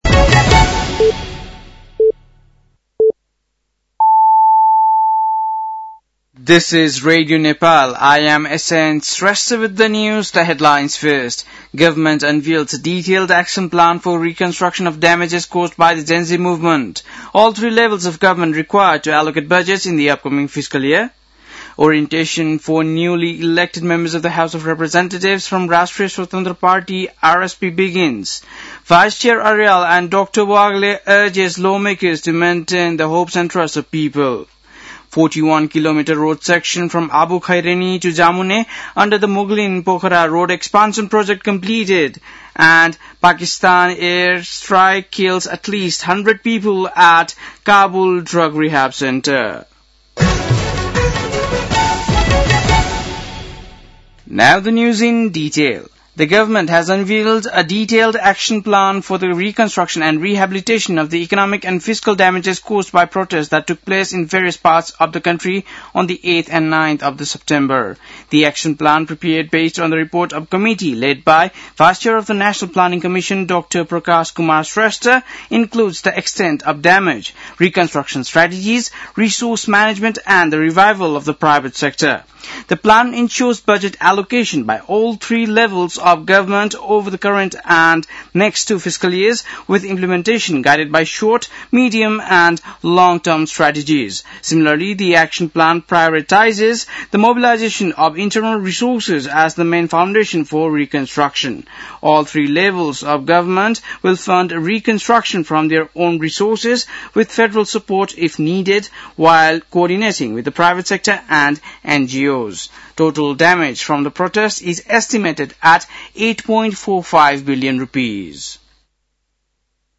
बेलुकी ८ बजेको अङ्ग्रेजी समाचार : ३ चैत , २०८२
8-pm-english-news-12-03.mp3